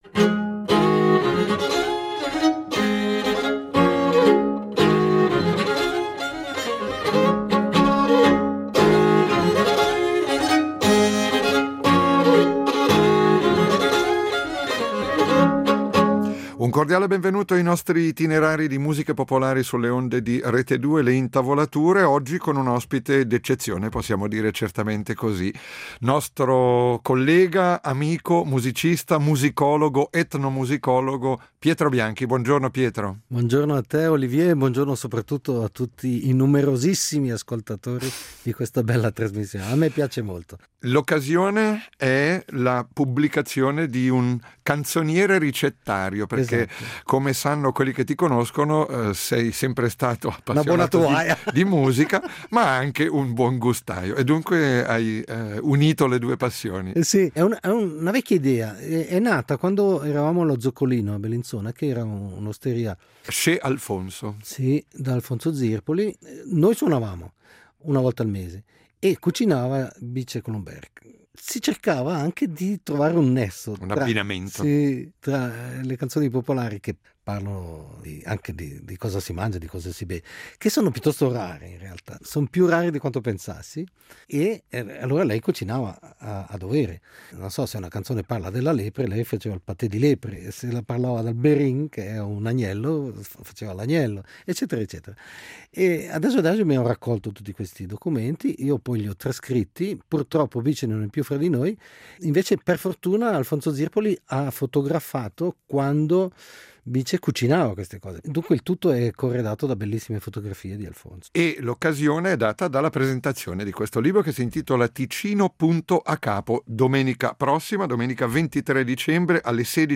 Itinerari di musica popolare